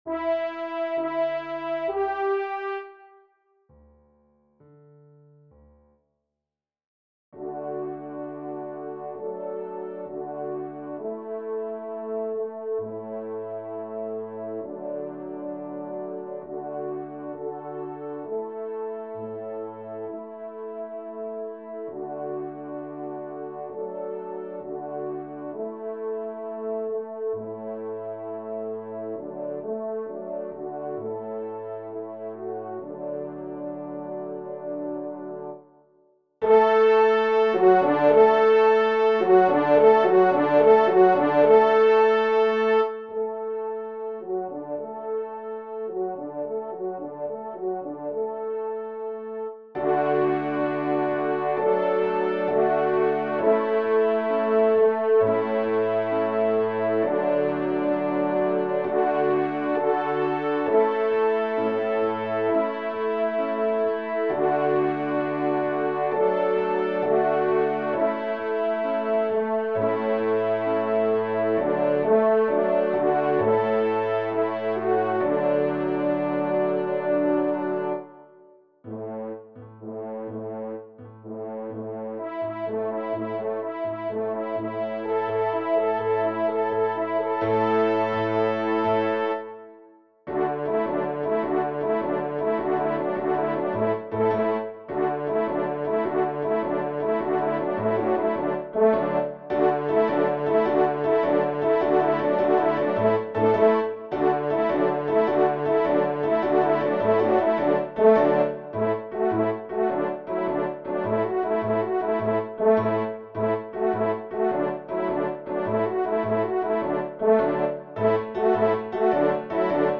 Genre :  Divertissement pour Trompe ou Cor et Piano
ENSEMBLE (Complet)